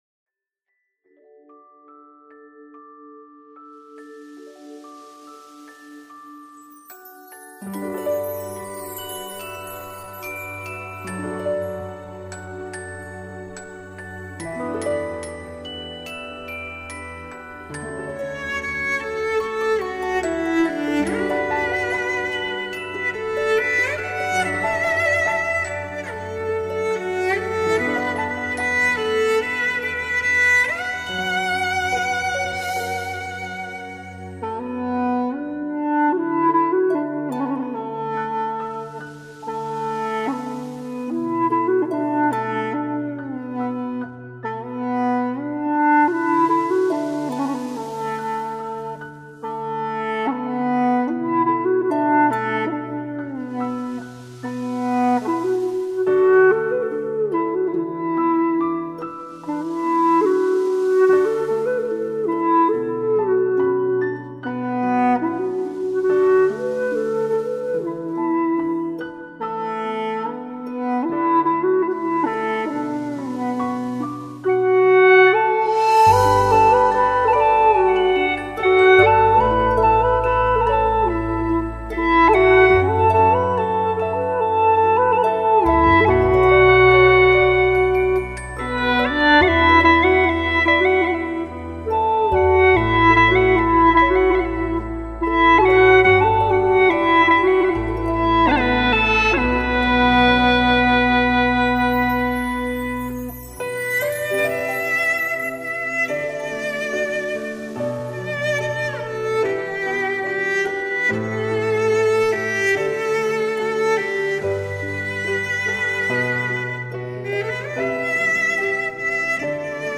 调式 : D 曲类 : 古风